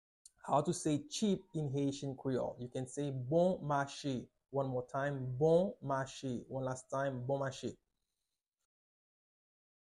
Listen to and watch “Bon mache” audio pronunciation in Haitian Creole by a native Haitian  in the video below:
4.How-to-say-Cheap-in-Haitian-Creole-–-Bon-mache-pronunciation.mp3